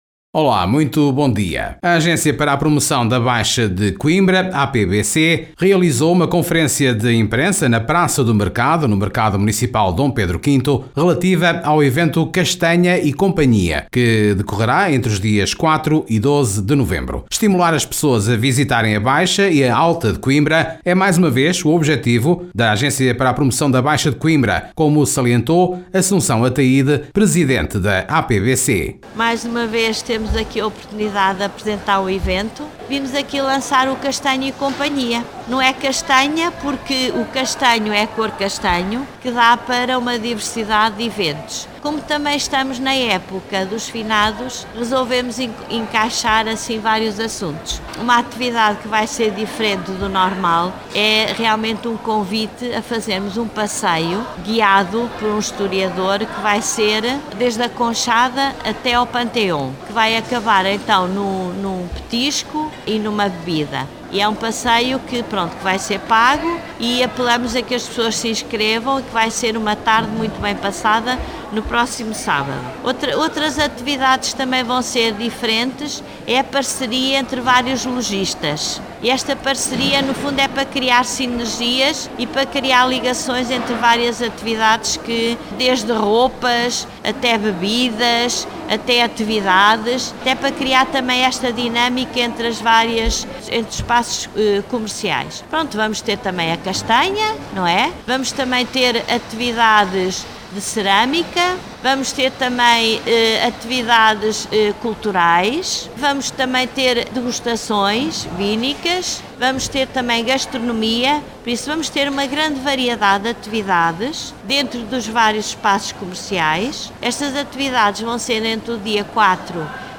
A Agência para a Promoção da Baixa de Coimbra (APBC) apresentou em conferência de imprensa, na Praça do Mercado (Mercado Municipal D. Pedro V), o evento “Castanh@ E Companhia”, que decorrerá entre os dias 04 e 12 de Novembro.